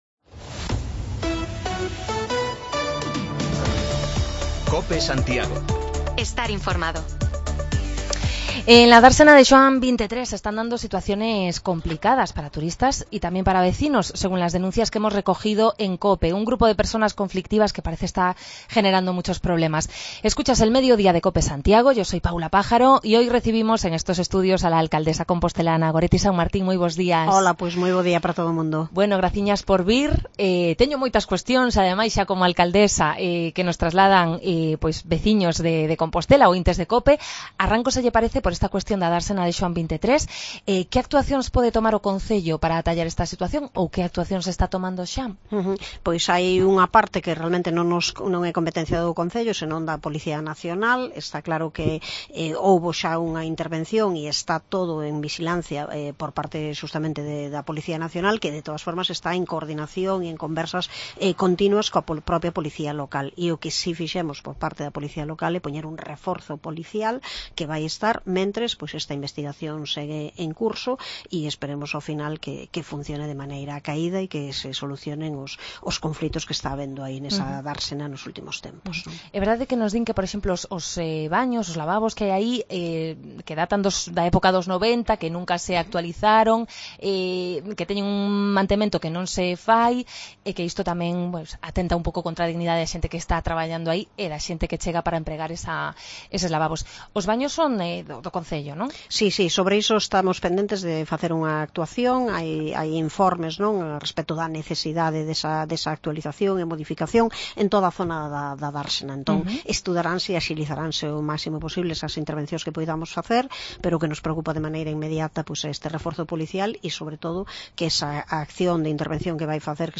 Entrevista a la alcaldesa de Santiago, Goretti Sanmartín: respondió a las preguntas de los oyentes de Cope y también a distintas cuestiones de la actualidad local, como las quejas por la venta y el consumo de droga en el entorno de la dársena de Xoán XXIII, las negociaciones sobre la parcela del antiguo Peleteiro o los preparativos para la celebración en Santiago del ECOFÍN.